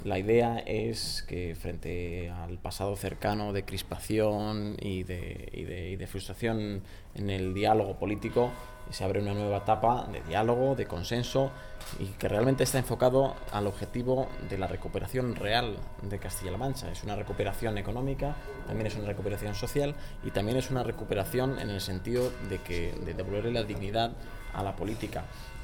portavoz_gobierno_-_dialogo.mp3